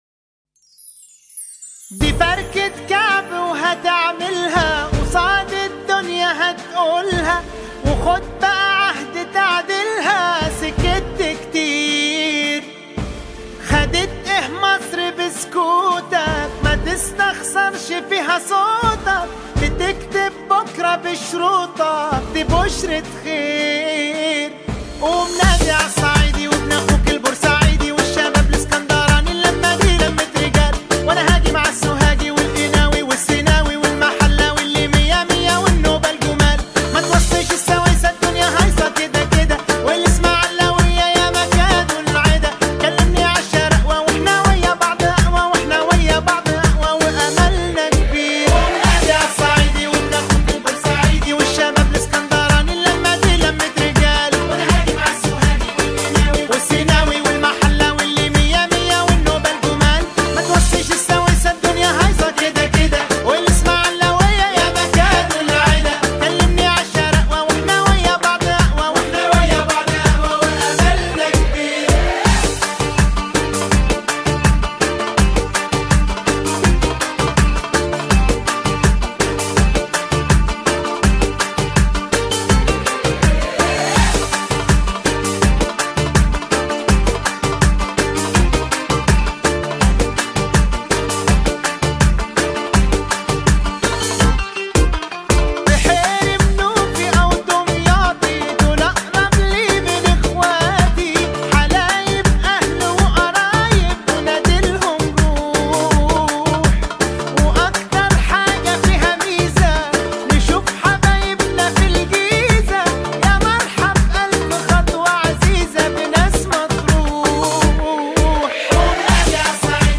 آهنگ عربی شاد برای رقص